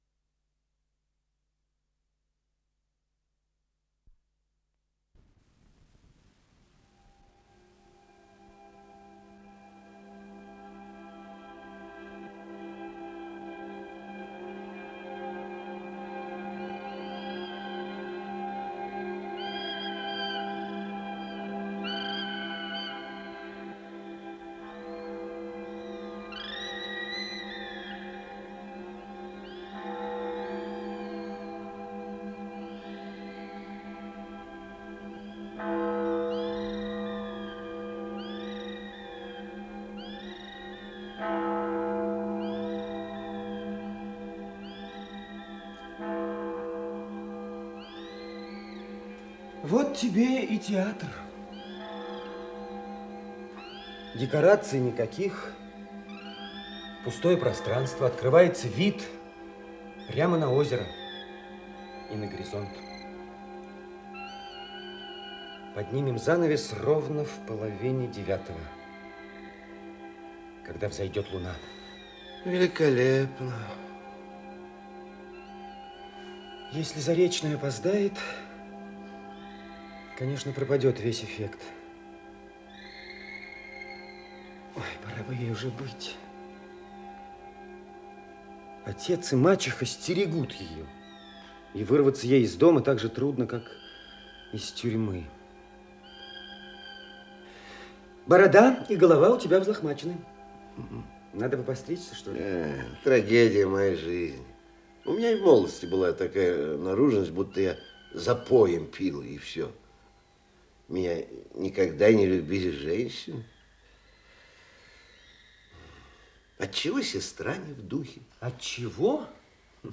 Исполнитель: Артисты МХАТ СССР им. Горького
Спектакль